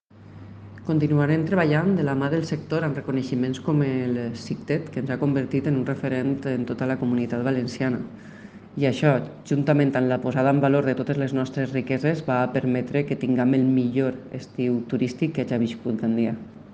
Escucha aquí a la concejala de
Turismo, Balbina Sendra (audio)